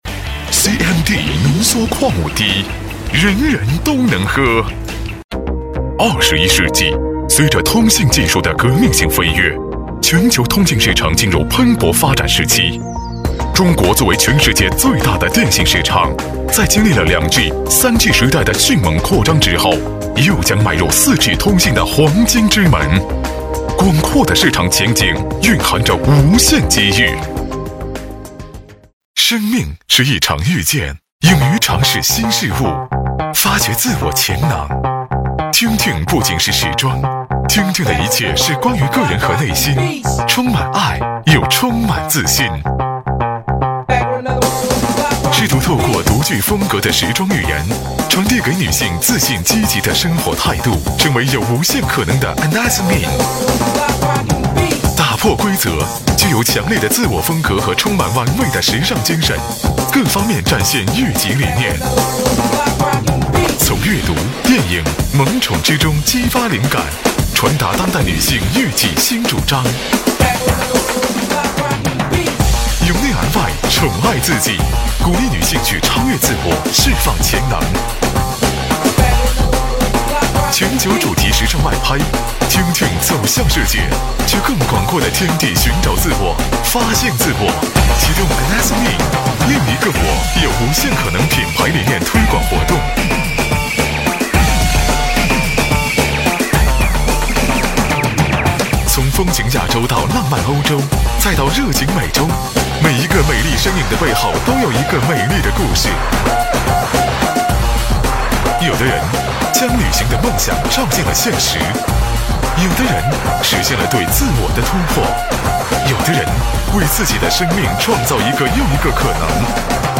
• 男S355 国语 男声 宣传片-tune tune-广告宣传-动感欢快 大气浑厚磁性|沉稳|科技感